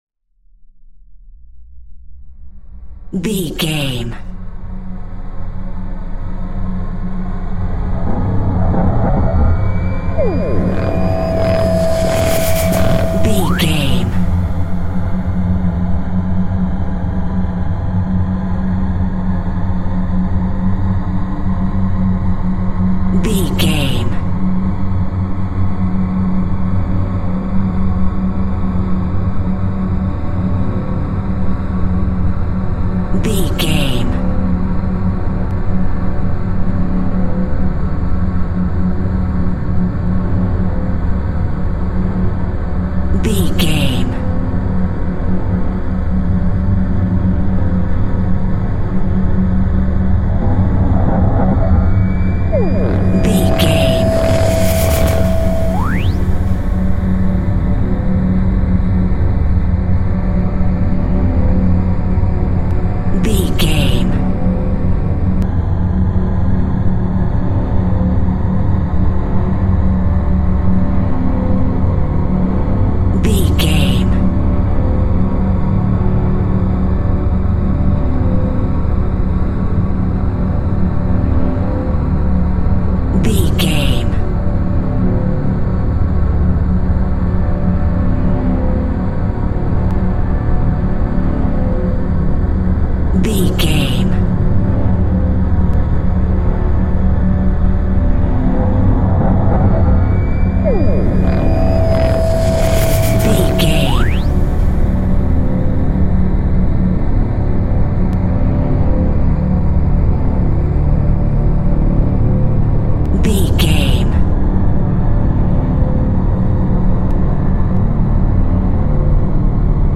Haunted Space Music.
Atonal
Slow
ominous
dark
suspense
eerie
synth
keyboards
ambience
pads
eletronic